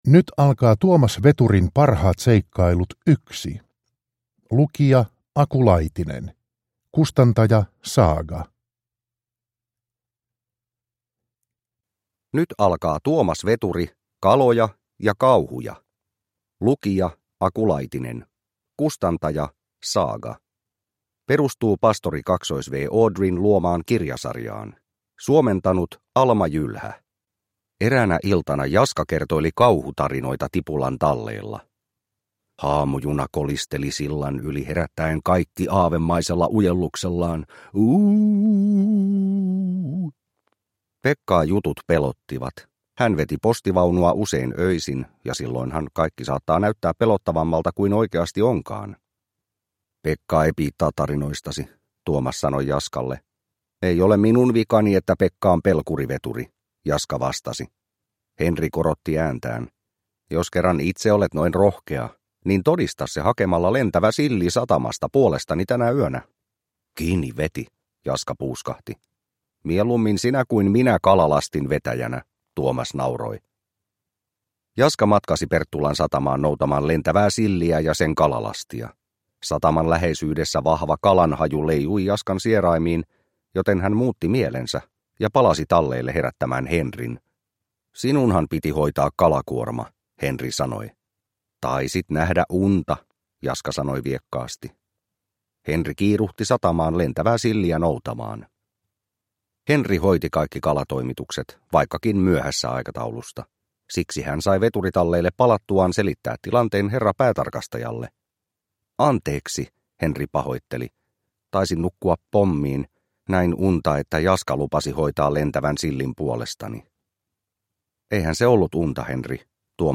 Tuomas Veturin parhaat seikkailut 1 – Ljudbok